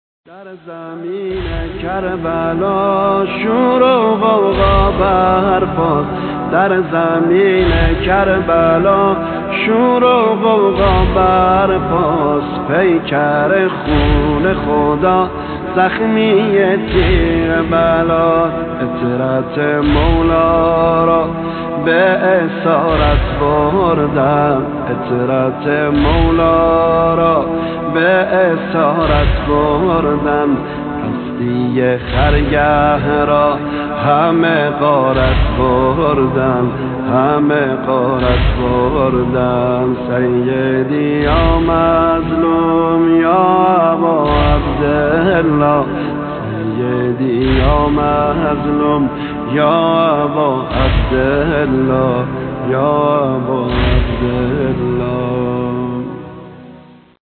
کد پیشواز مداحی